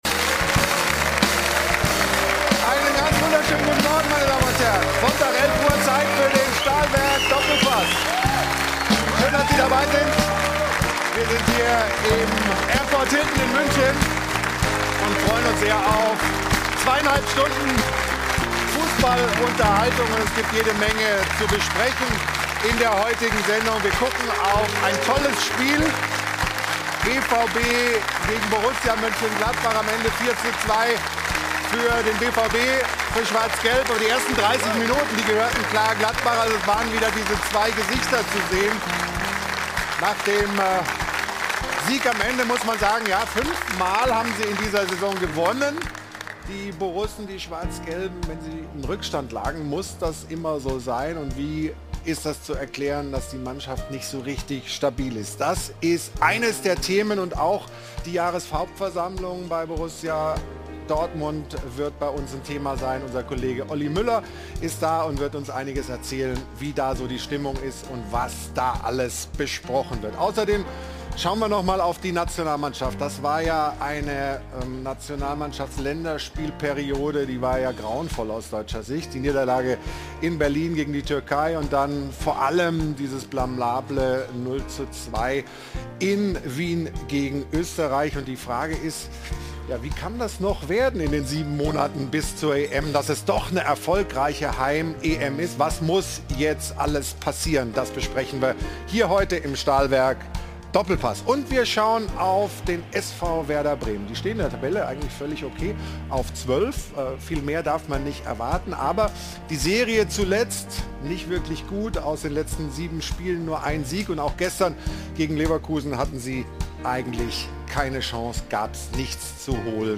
zugeschaltet und berichtet live von der Jahreshauptversammlung